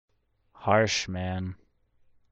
Harsh man